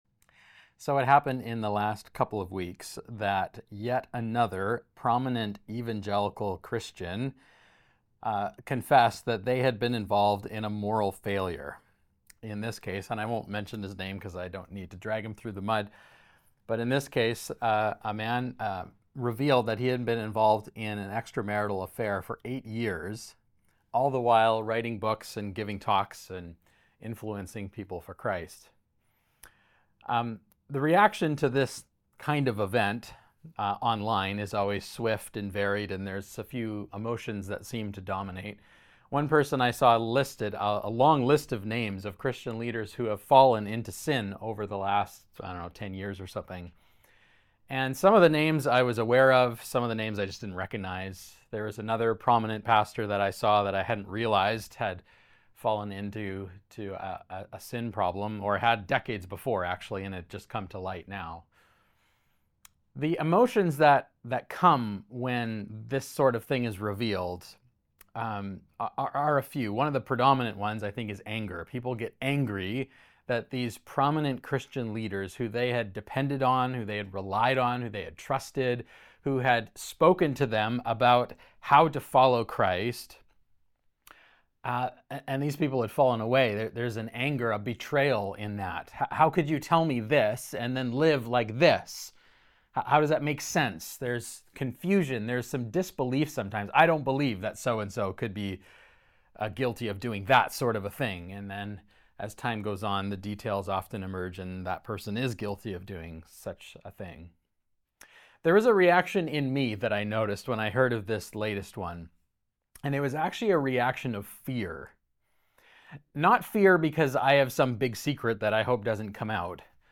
Ross Road Community Church - Podcast | Ross Road Community Church